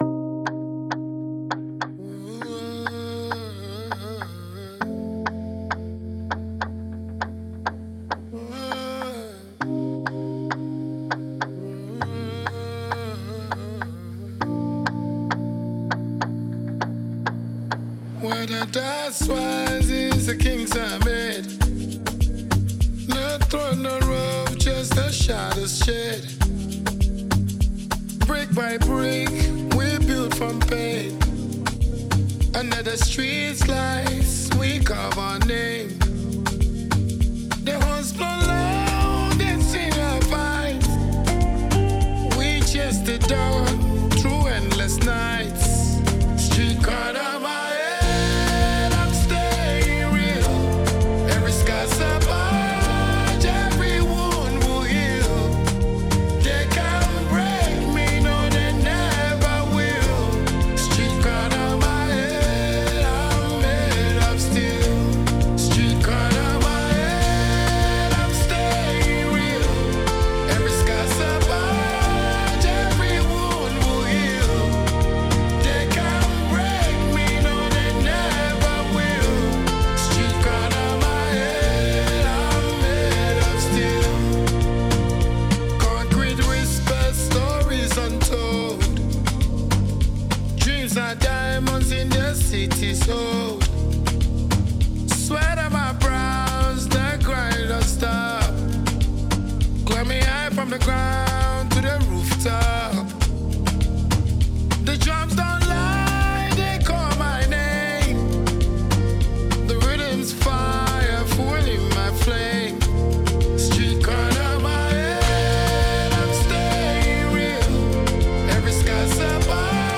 African Dancehall